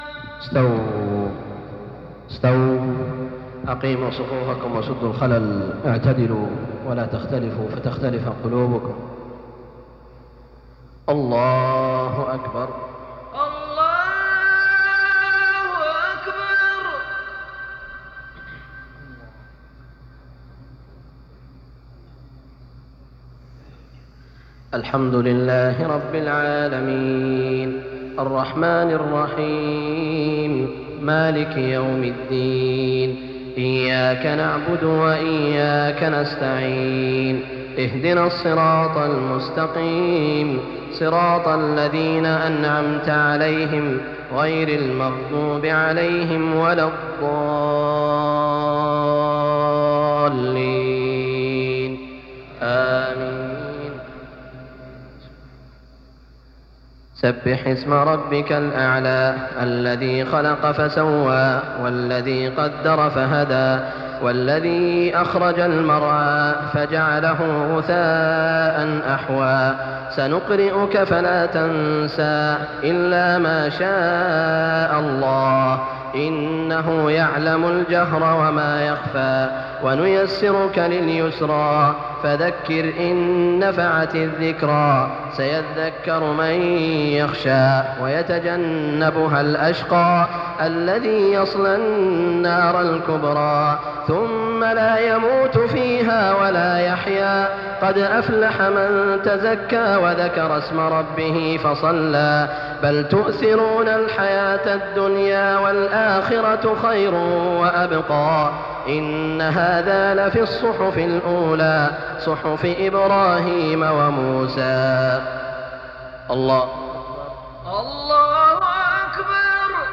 صلاة الجمعة 3-8-1417 سورتي الأعلى و الغاشية > 1417 🕋 > الفروض - تلاوات الحرمين